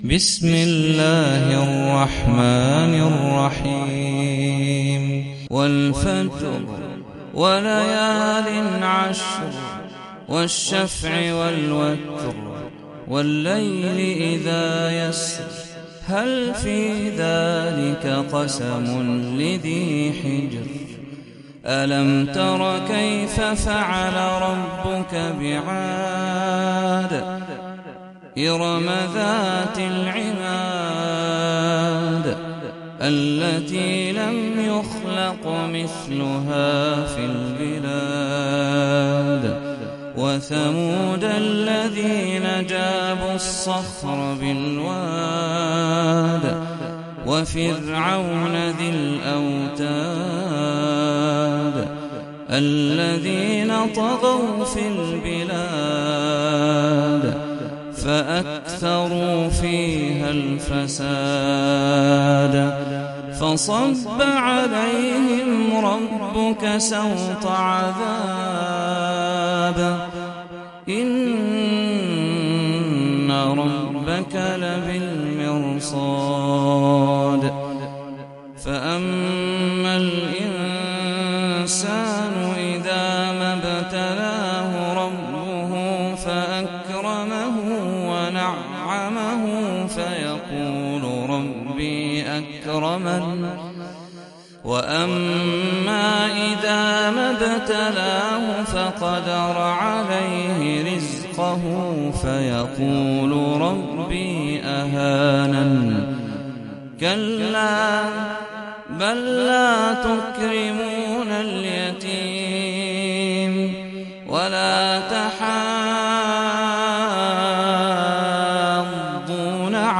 سورة الفجر - صلاة التراويح 1446 هـ (برواية حفص عن عاصم)